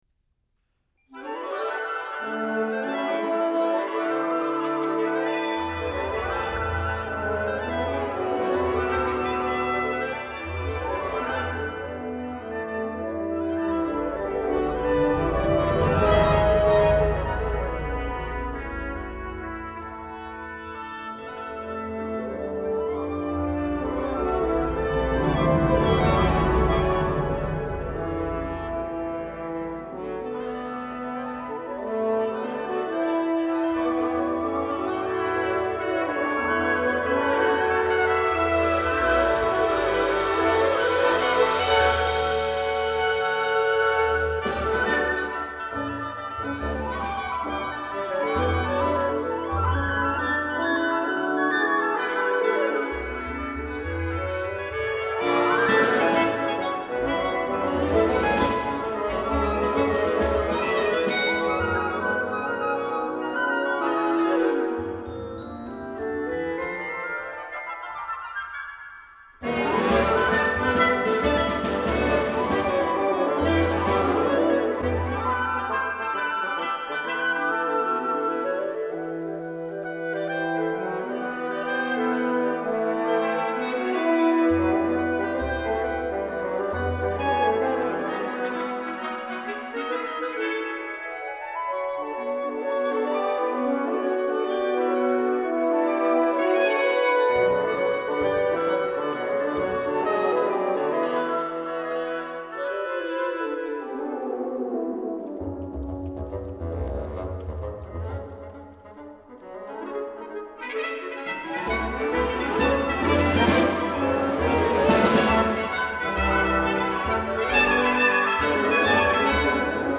A tone poem
Instrumentation: 2fl 2ob 2cl 2bn 2hn 1tp 1per 1cb
Download the synthesized mp3